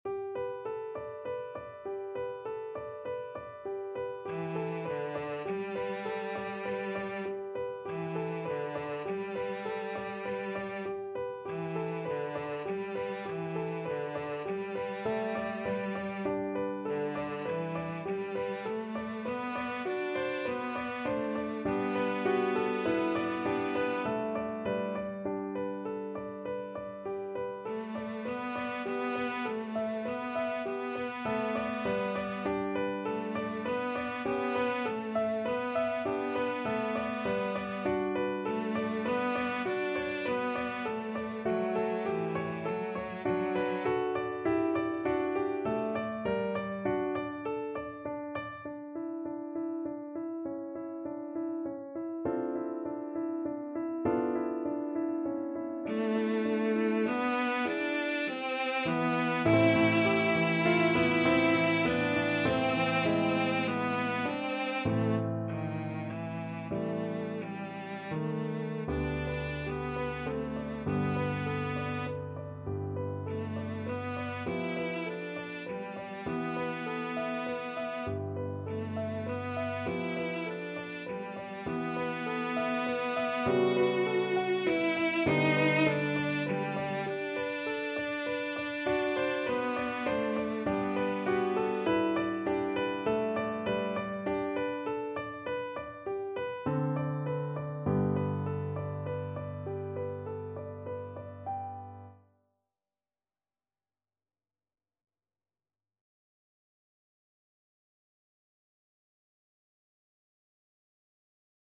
9/4 (View more 9/4 Music)
~ = 120 Allegretto
Classical (View more Classical Cello Music)